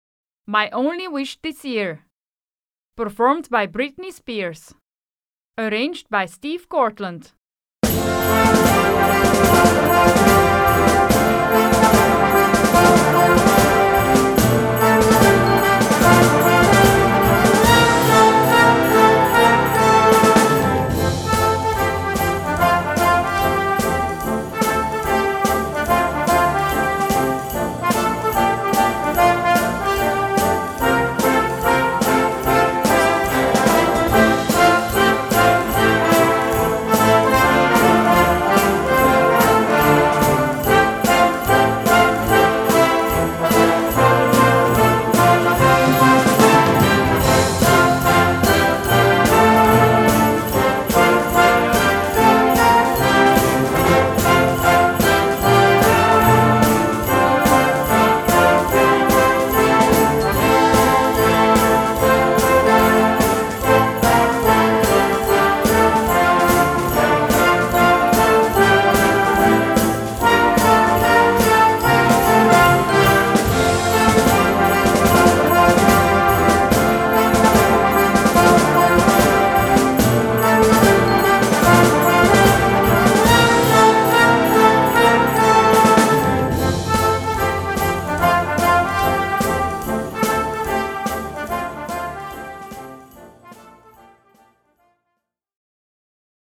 Gattung: Moderner Weihnachtstitel
Besetzung: Blasorchester